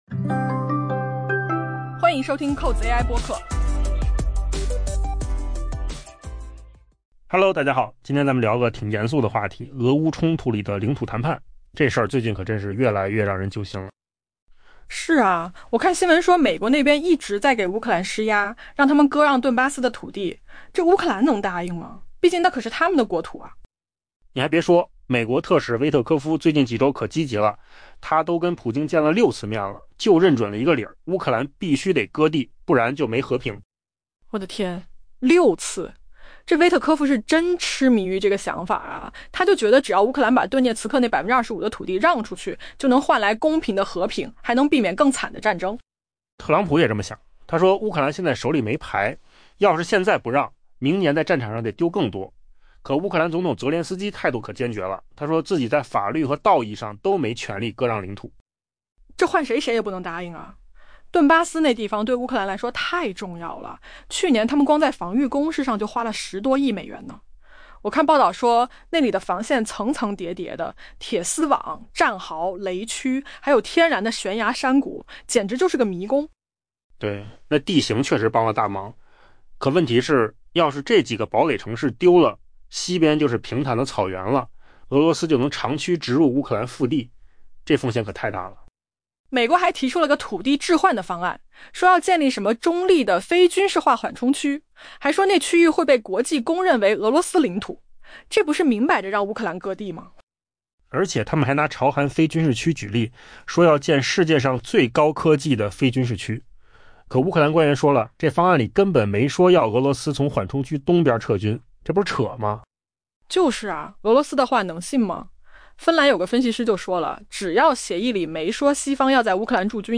AI 播客：换个方式听新闻 下载 mp3 音频由扣子空间生成 随着美国主导的结束俄乌冲突的努力艰难推进，一个巨大的障碍可能会让协议彻底脱轨：领土让步。